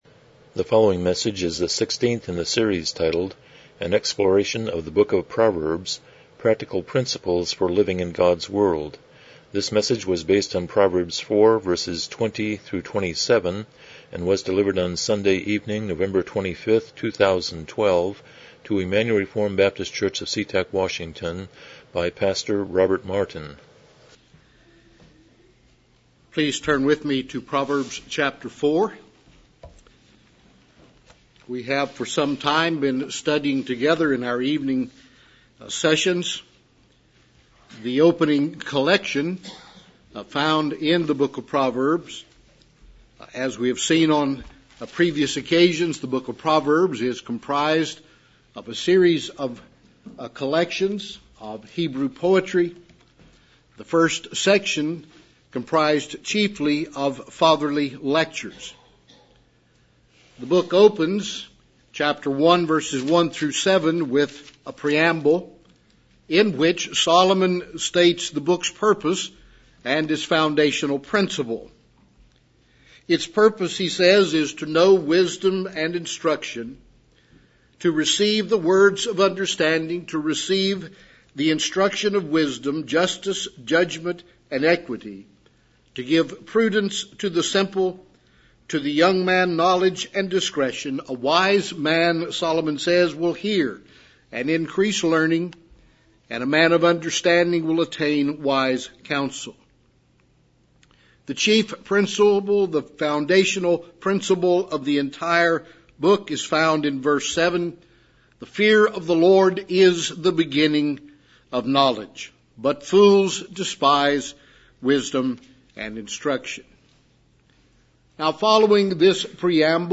Proverbs 4:20-27 Service Type: Evening Worship « 19 The Sermon on the Mount